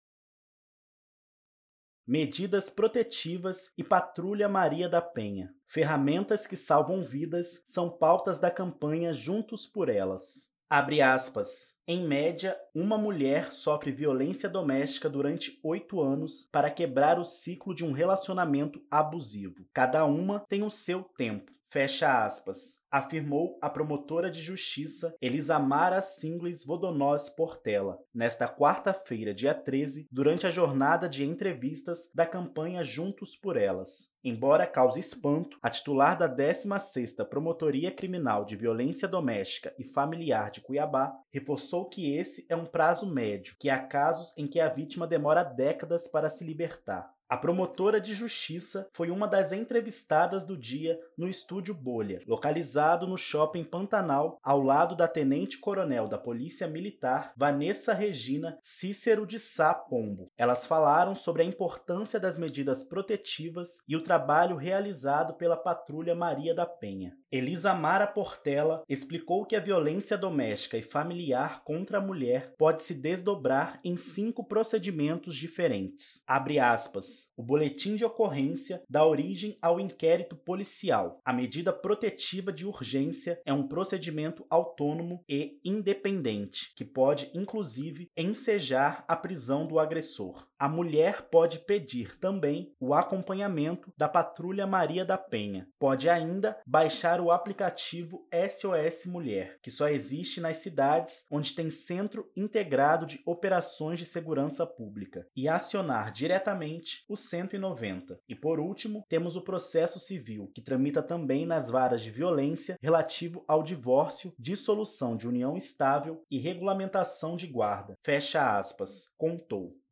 Elas falaram sobre “A importância das medidas protetivas e o trabalho realizado pela Patrulha Maria da Penha”.